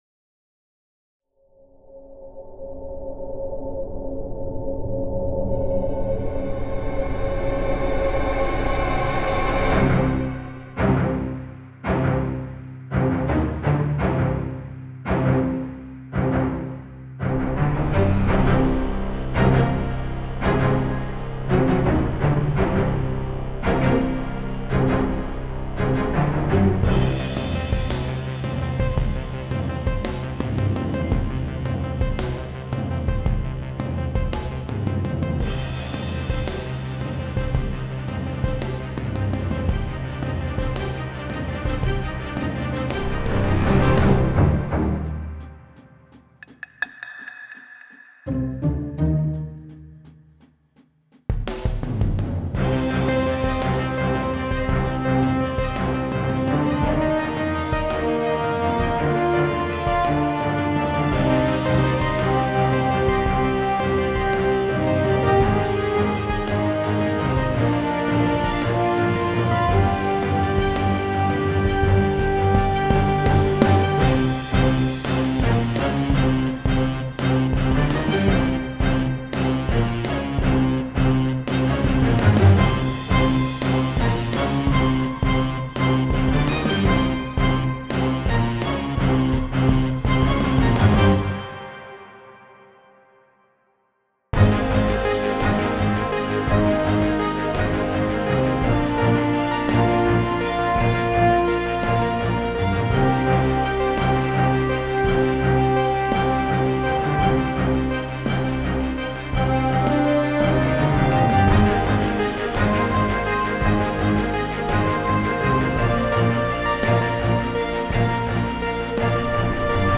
Hark! Is that a ravenous horde I hear approaching?
ominously